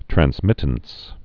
(trăns-mĭtns, trănz-)